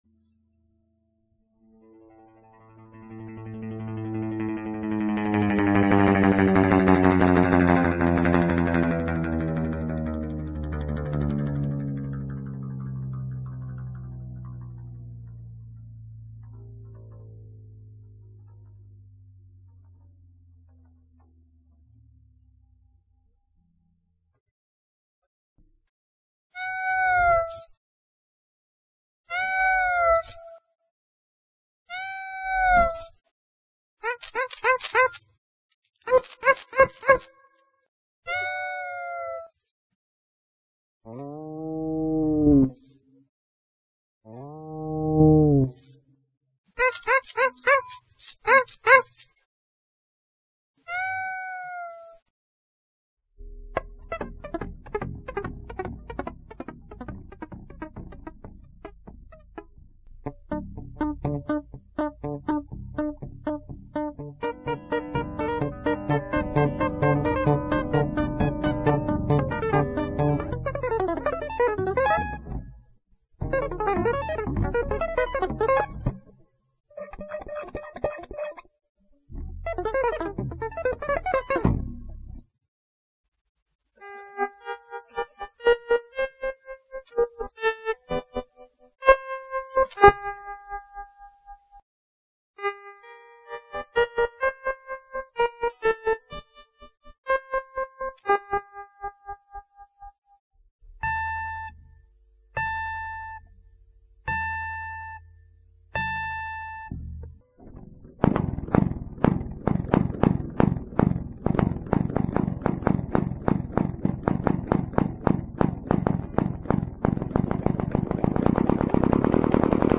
Поэтому и звук получается очень сильный и хлёсткий.